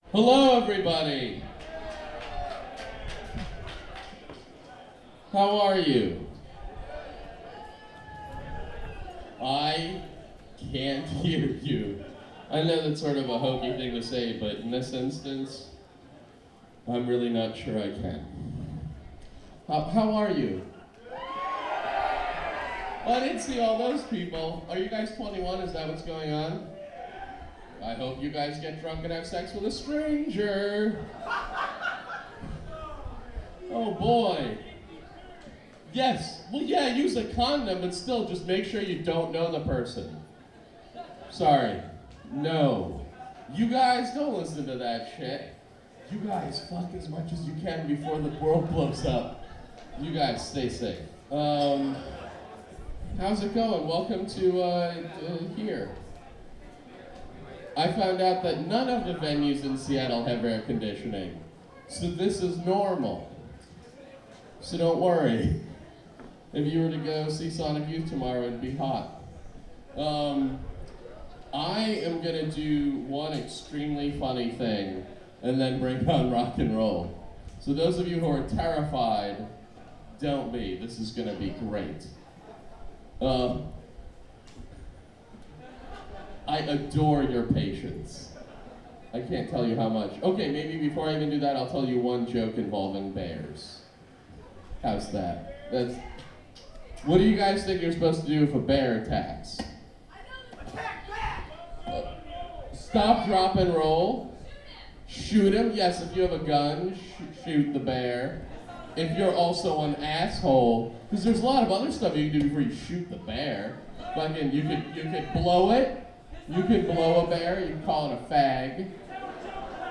On the first two nights, however, Eugene volunteered two five minute segments of comedy that still make me laugh as much as they did when he told them the first time.
Technical Note: the clip from 2006-06-29 is just an AUD source and the clip from 2006-06-30 is an AUD/SBD matrix.
Eugene Mirman – 2006-06-29 Neumo’s – Seattle, WA